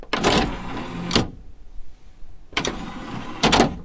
烘干机
描述：开、关，以及烘干机的低沉轰鸣声。
Tag: 洗衣 嗡嗡 干燥 哼哼